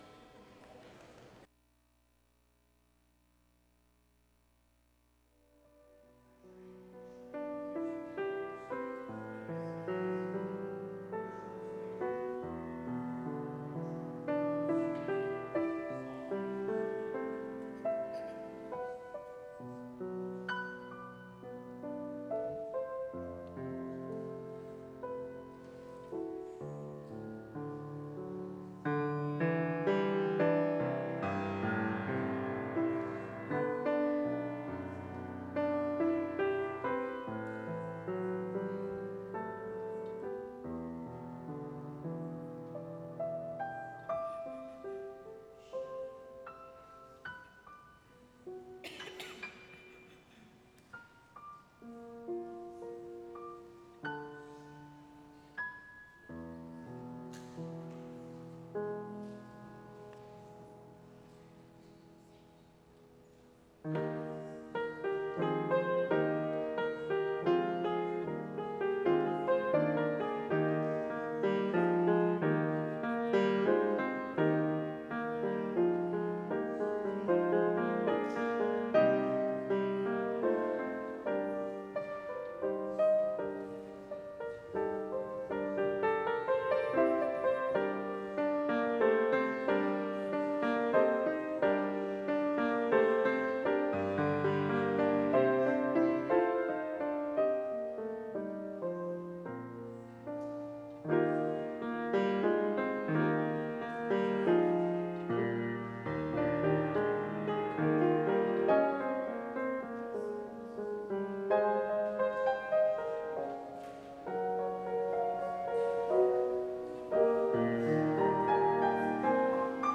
Worship Service Sunday, October 5, 2025